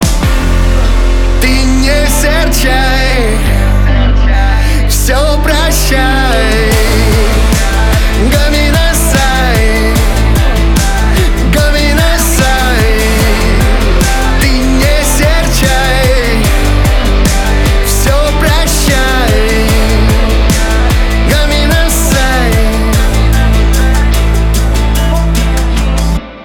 поп
битовые
гитара